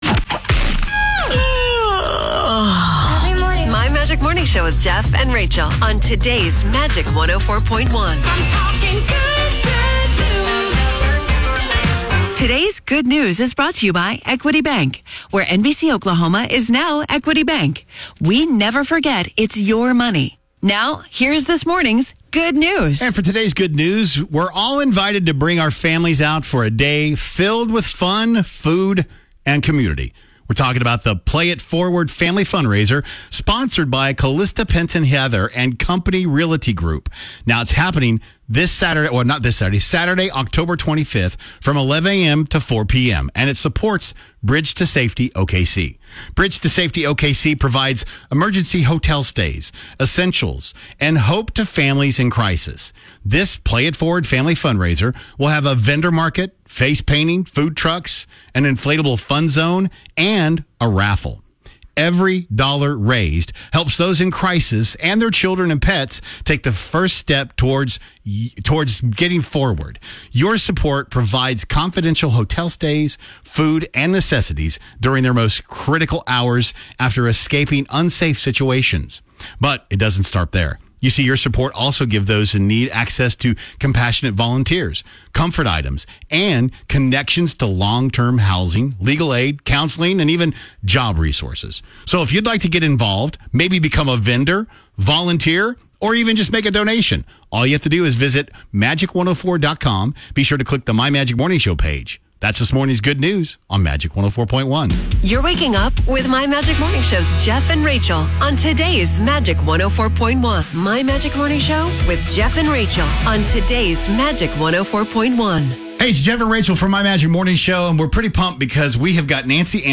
104.1 KMGL Radio Show Play It Forward Fundraiser & Interview Segment With Bridge2Safety OKC